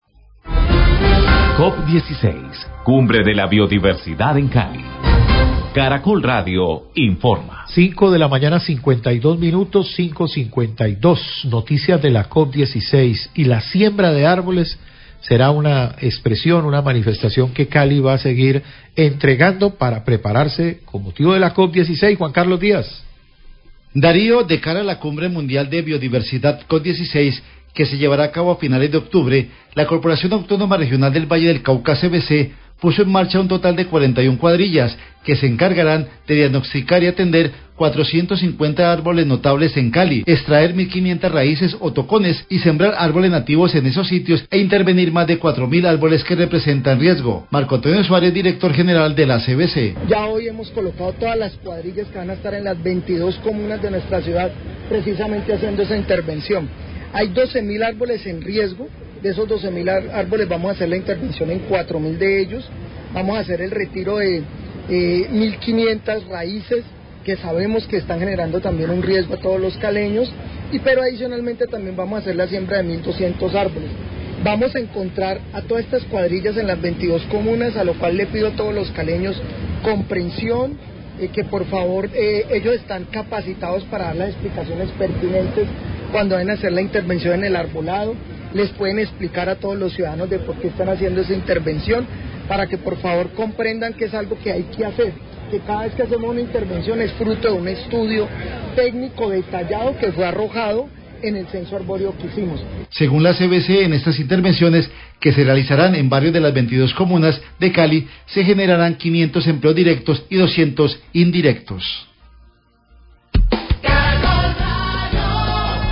Dir. general CVC habla de cuadrillas que realizarán mantenimiento arboreo y siembra en Cali,
Radio